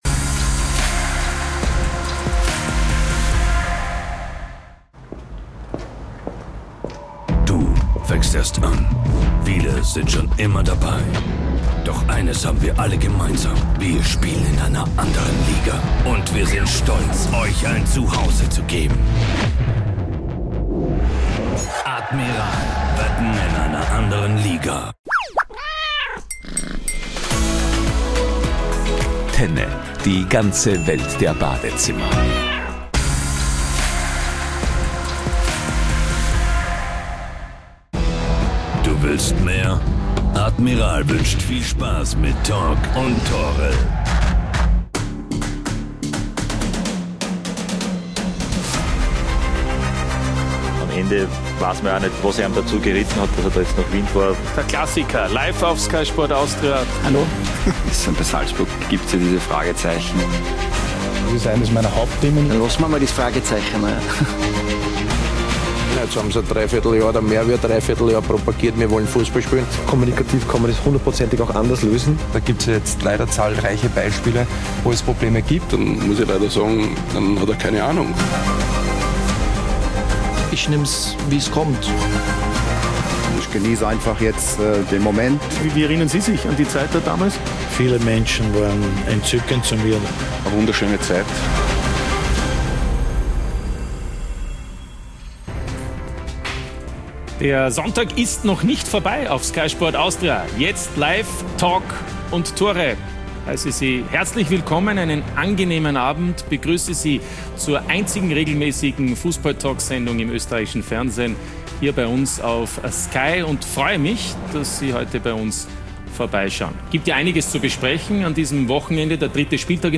„Talk und Tore“ ist die erste und einzige Fußballtalksendung in Österreich. Wir liefern neue Blickwinkel, Meinungen und Hintergründe zu den aktuellen Themen im österreichischen Fußball und diskutieren mit kompetenten Gästen die aktuellen Entwicklungen.